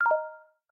pause-retry-click.ogg